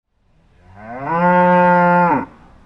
Sound Effects
Cow Mooing Type 03